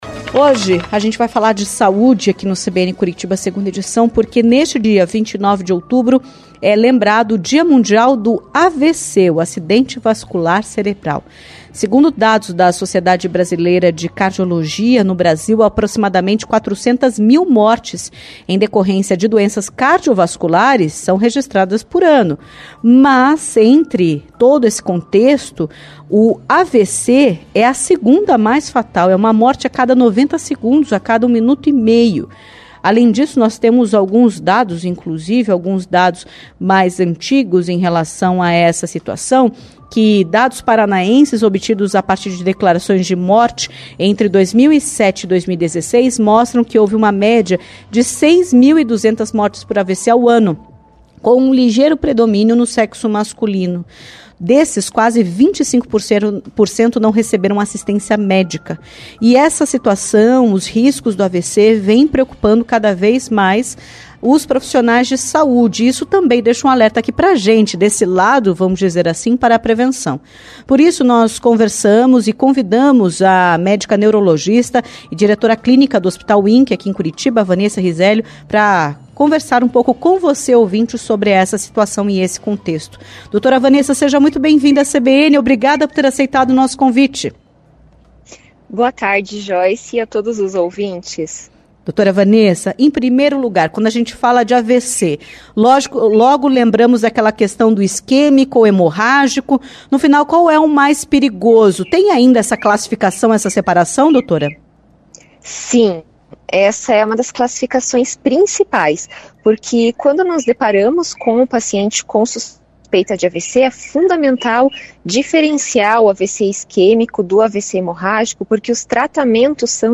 Entrevista-AVC-29-10.mp3